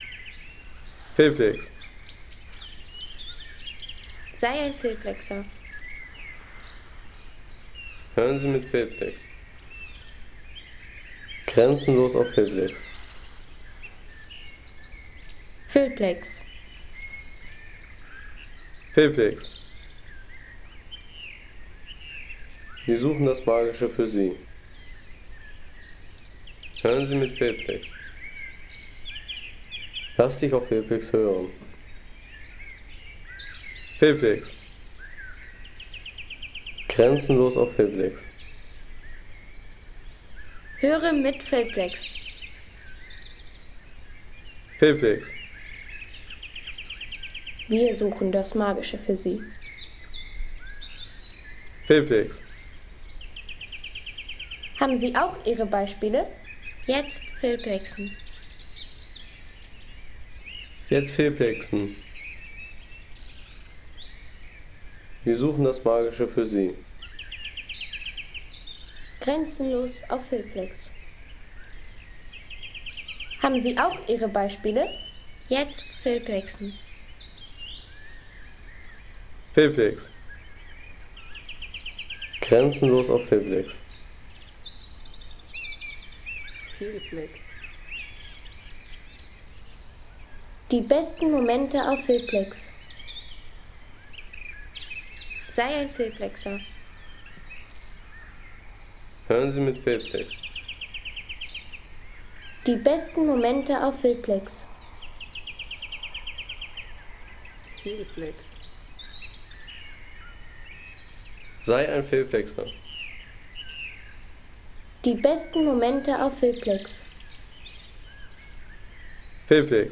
Jesteburger Wald am Morgen | Waldstimmung bei Tagesbeginn
Authentische Morgen-Waldatmosphäre aus Jesteburg mit lebendigem Vogelgesang und ruhiger Waldtiefe.
Bringe den lebendigen und zugleich entspannenden Klang eines erwachenden Waldes in Jesteburg in Filme, Postcards, Dokus und atmosphärische Szenen.